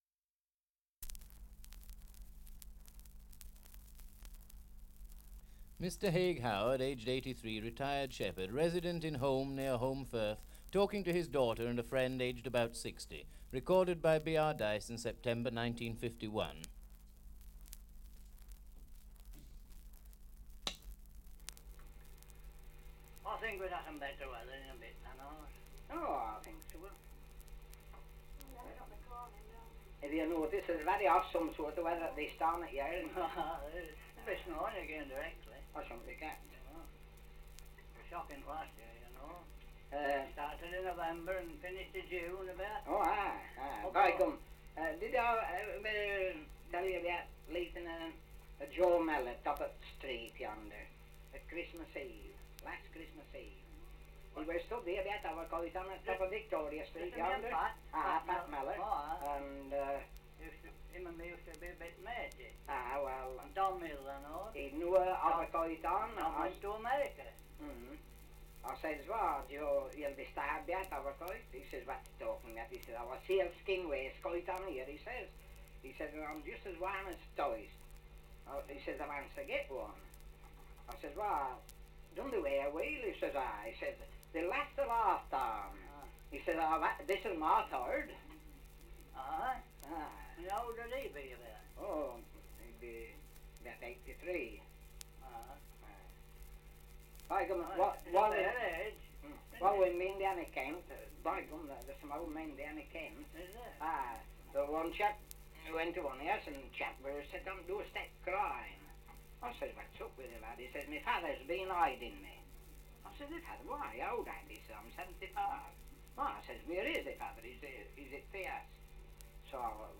Title: Survey of English Dialects recording in Holmbridge, Yorkshire
78 r.p.m., cellulose nitrate on aluminium